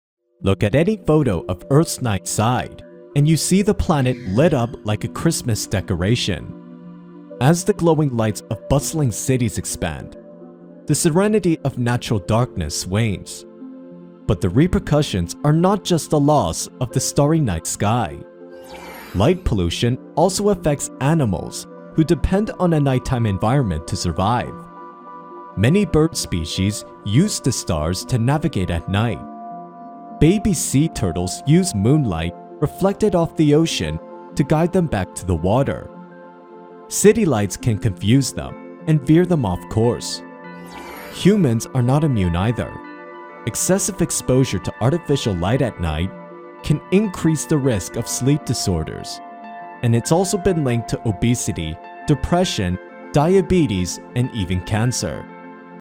Retell Lecture - Light Pollution